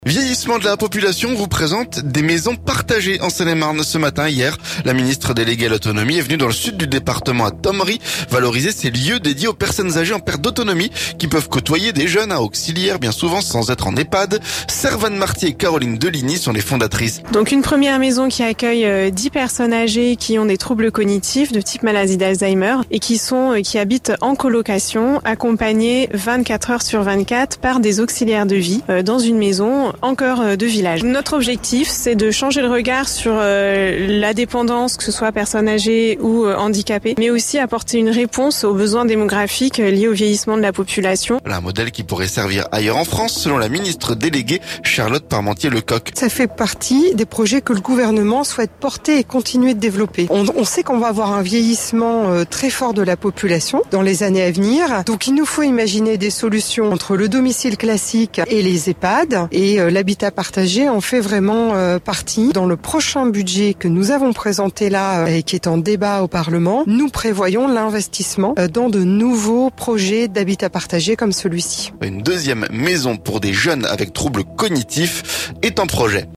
AUTONOMIE - Les maisons partagées, nouvelle solution? Notre reportage à Thomery